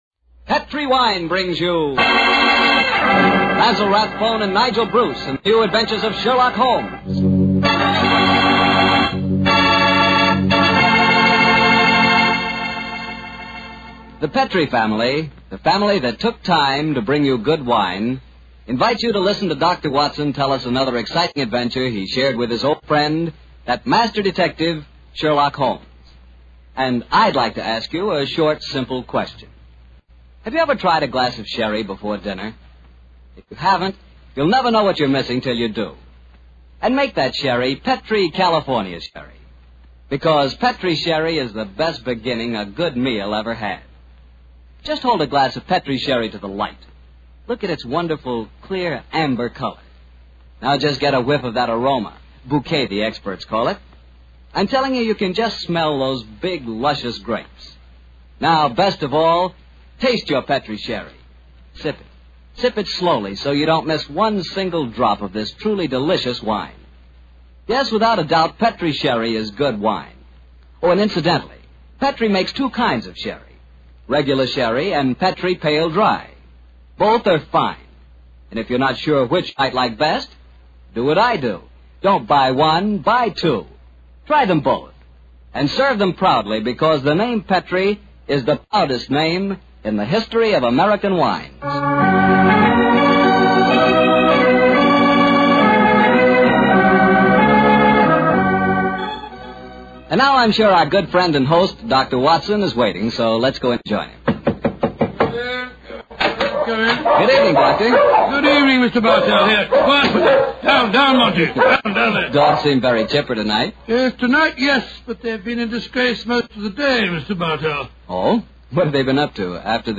Radio Show Drama with Sherlock Holmes - The Disappearing Scientists 1946